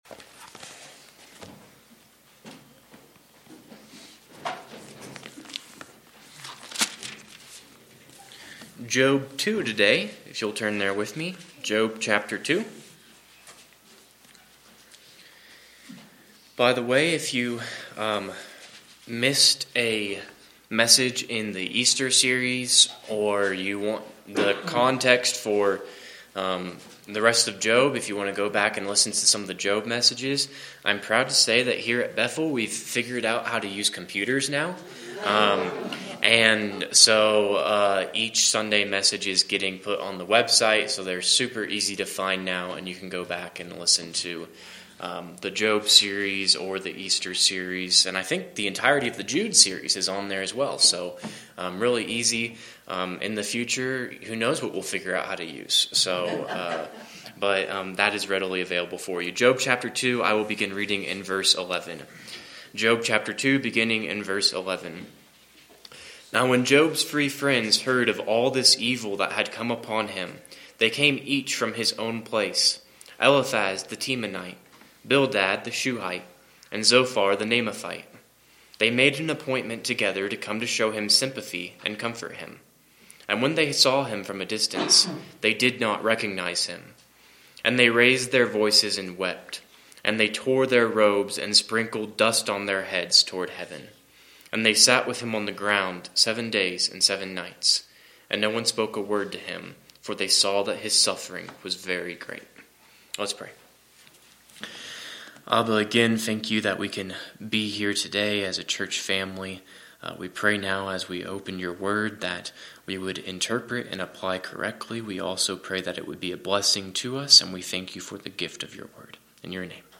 Sermons | Bethel Baptist Church
Preaching at Bethel Baptist Church in Laramie, Wyoming strives to exalt Jesus and teach what God says in His Word.